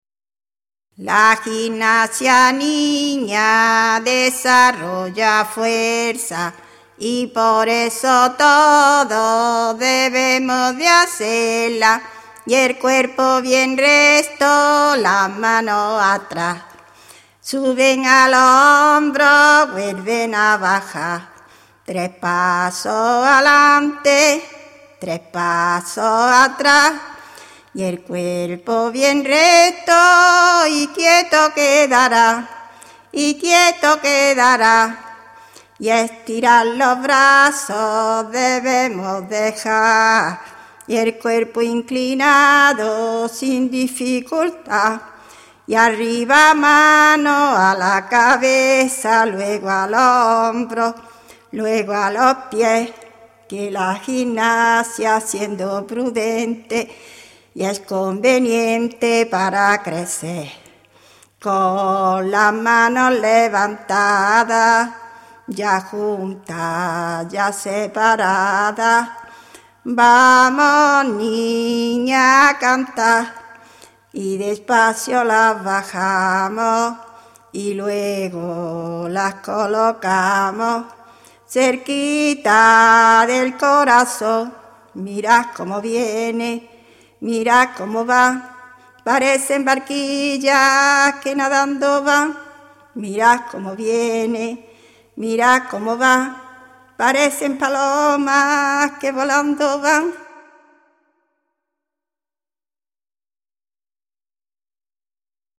Registros relacionados: En: 2º Certamen Narración Oral ; 3ª Semana de la Oralidad (abr.-sept. 2002).
Materia / geográfico / evento: Canciones escolares Icono con lupa